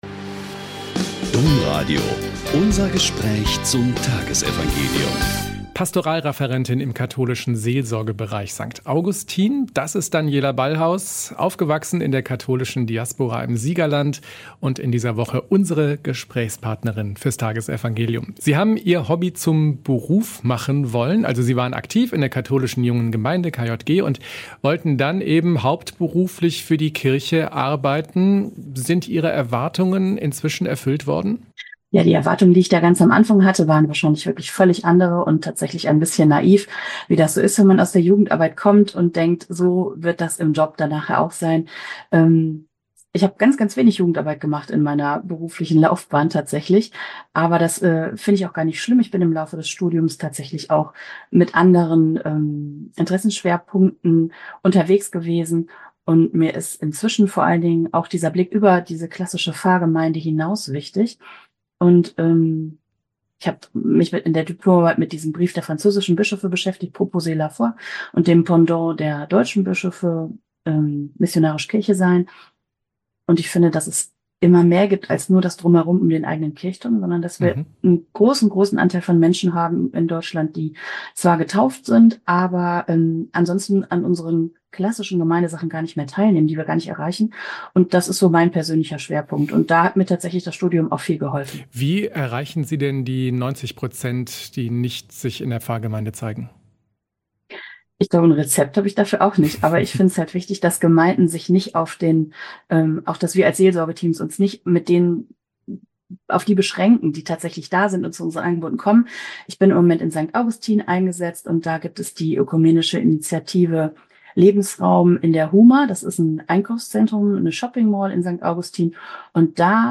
Mt 6,1-6.16-18 - Gespräch